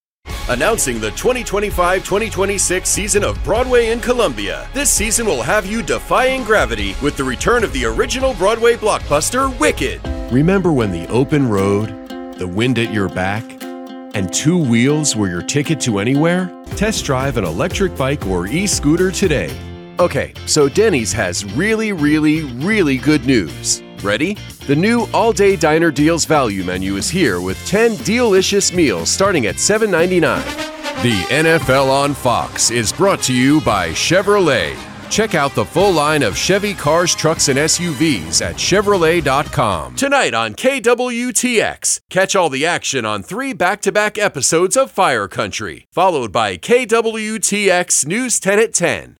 English (American)
Commercial Demo
I work full time out of my home studio.
Baritone